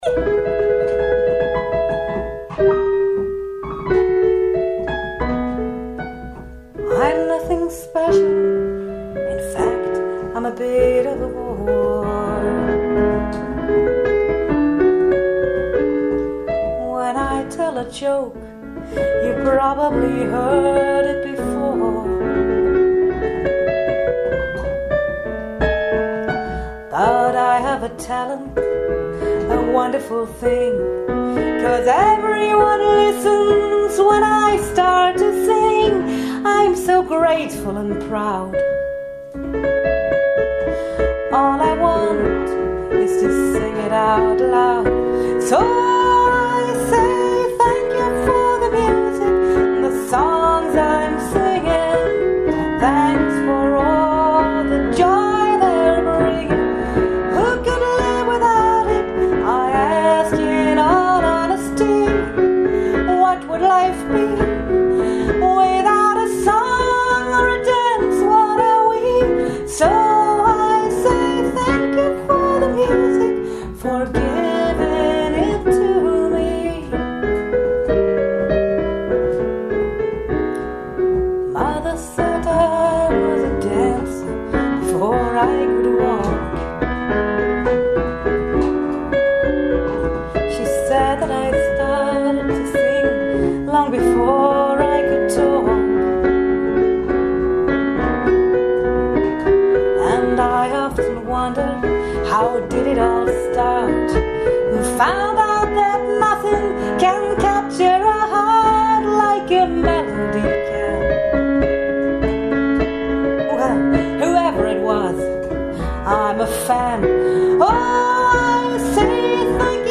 Zunächst müsst Ihr meinen Gesang ertragen. Geht aber denk ich ganz gut :-) Ich werde von einem Freund begleitet, der das Veröffentlichen erlaubt hat, aber nicht genannt werden will.